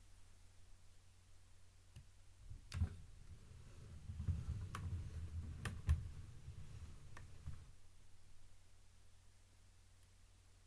Slow/Open drawer 3
Description - Wooden drawer, opens, slowly, drags,